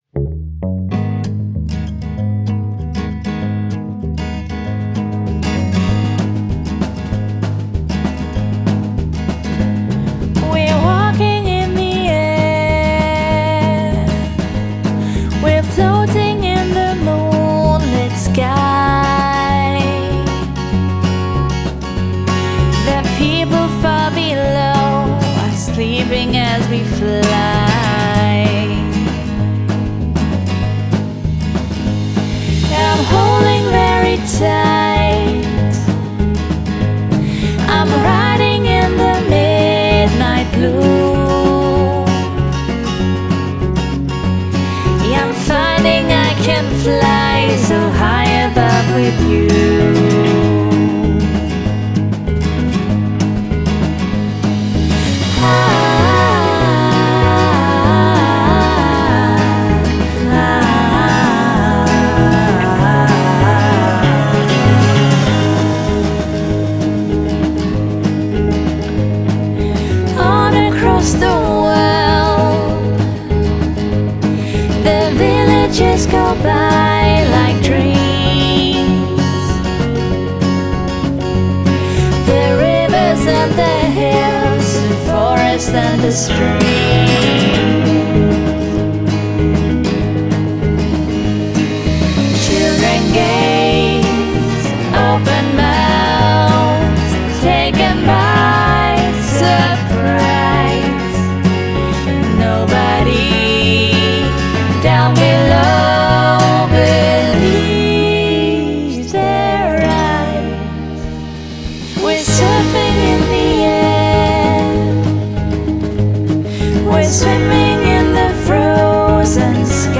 a rootsy, indie classic